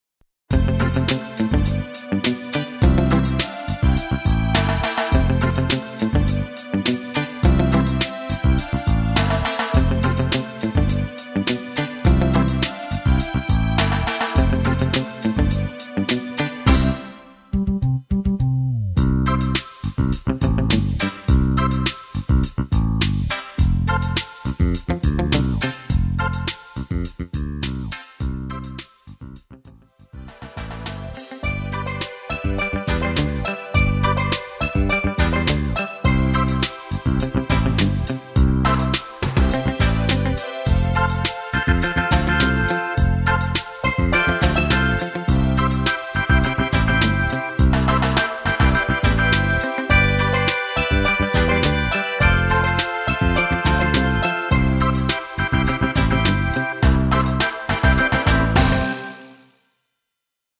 엔딩이 페이드 아웃이라 노래 부르기 편하게 아래의 가사 까지 하고 엔딩을 만들었습니다.(미리듣기 참조)
앞부분30초, 뒷부분30초씩 편집해서 올려 드리고 있습니다.